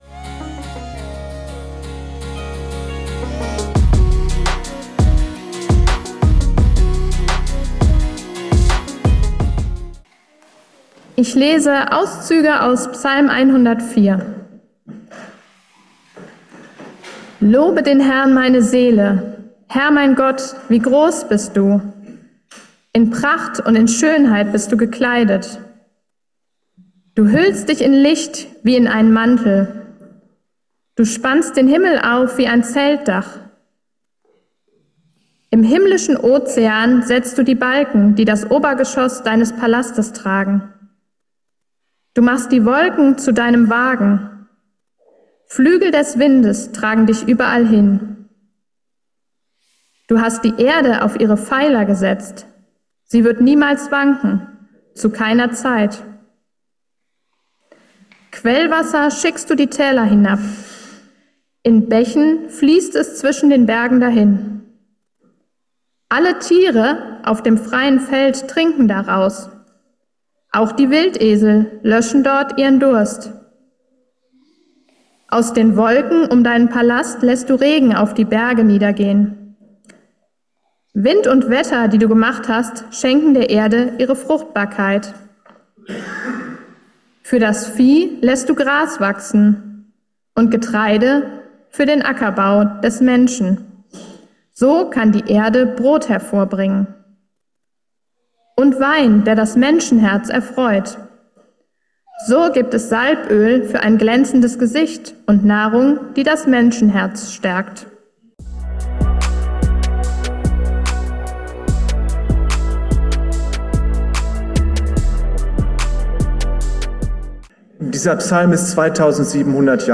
Erntedankfest: Entscheide dich für das Leben... ~ Geistliche Inputs, Andachten, Predigten Podcast